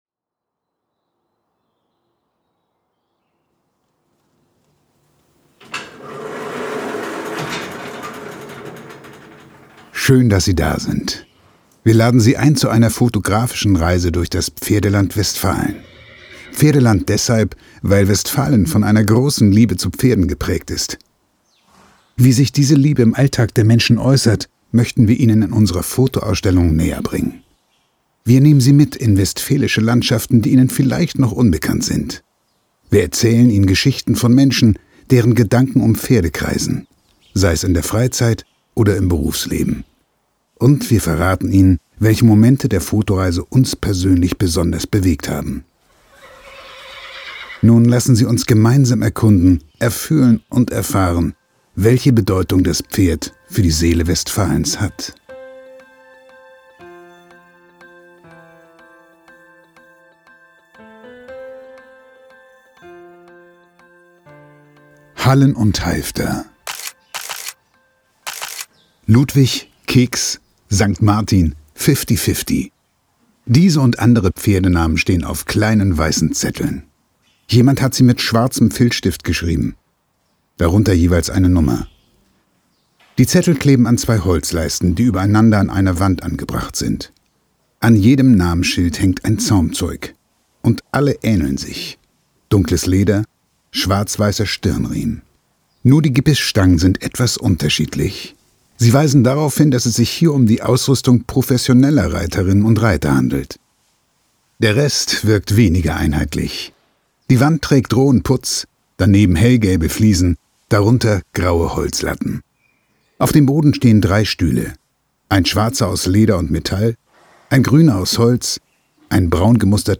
Unser Hörbuch genießen Sie am besten mit Kopfhörern .
pferde2020_hoerbuch_taste_1__intro_hallenundhalfter__master.mp3